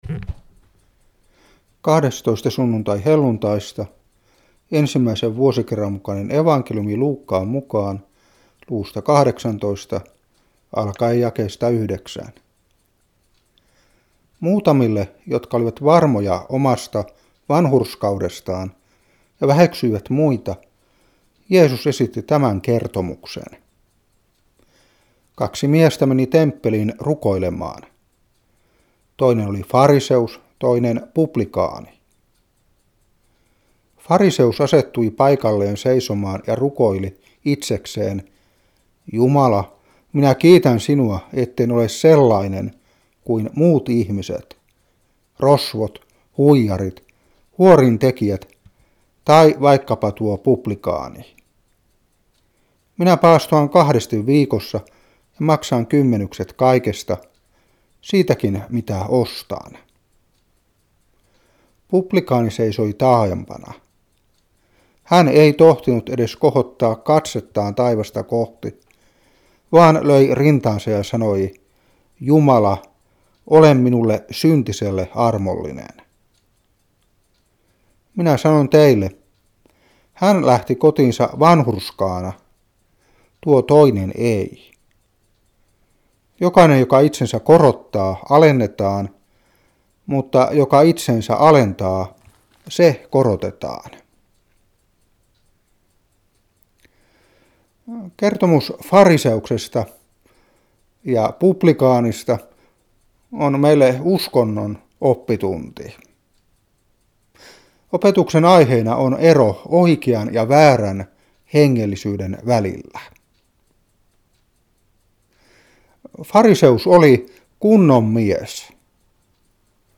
Saarna 2001-8.